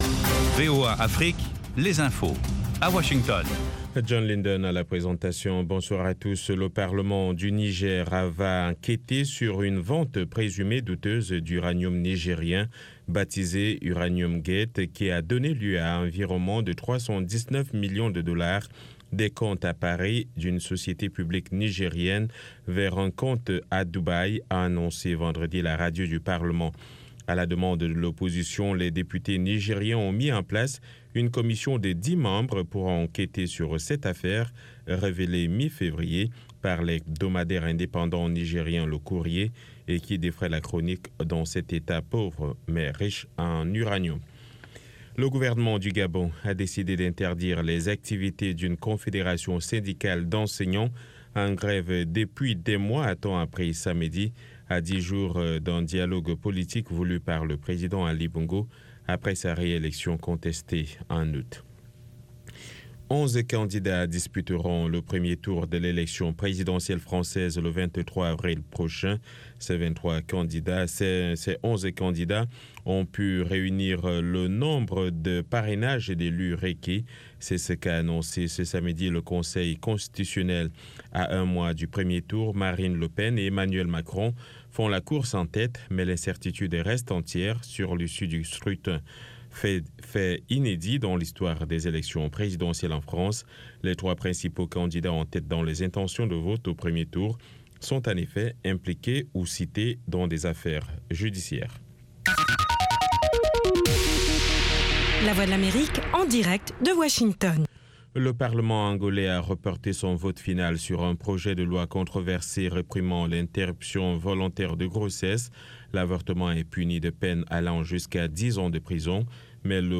- Les orchestres gigantesques et les groups qui ont grandement contribué a changer les mœurs et société, ce classement comprend divers genre musicaux (Rap, Rock, Pop, R&b etc.) afin de satisfaire le plus grand nombre.